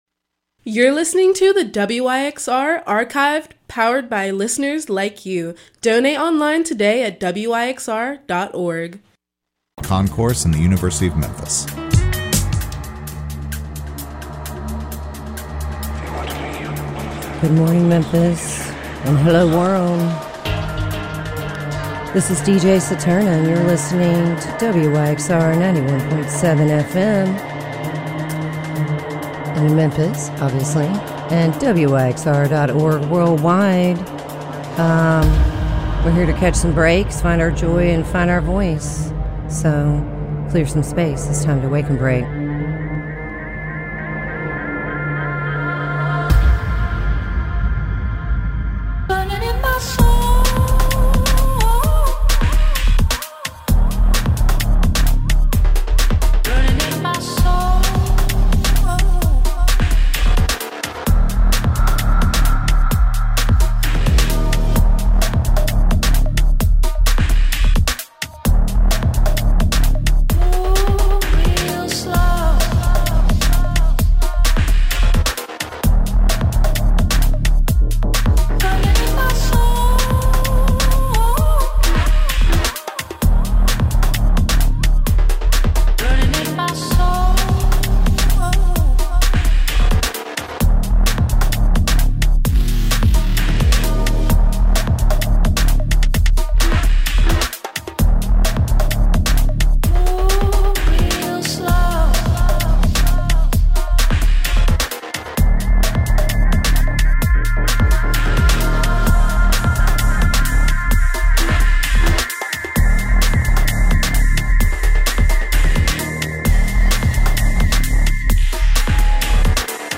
Breaks Electronica Jazz Soul Funk